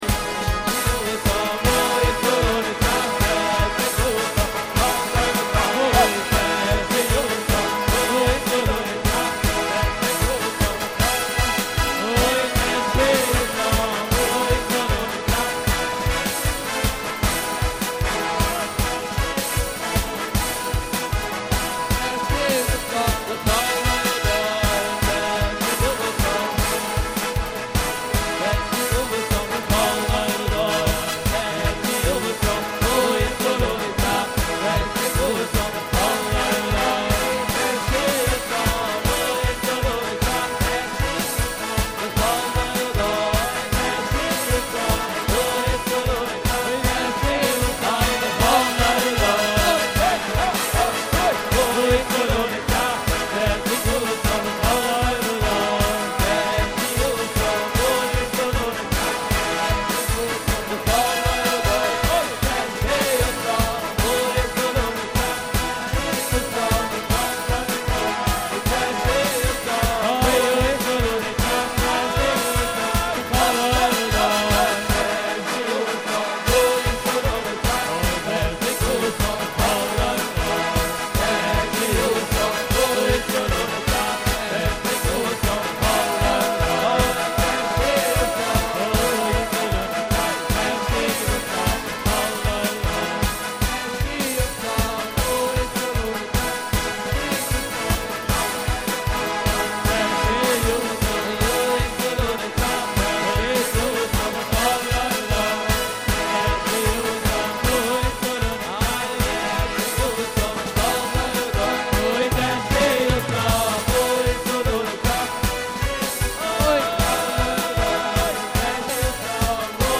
פורים - מחרוזות חסידיות מקפיצות